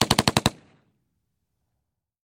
Звук автомата M16 при стрельбе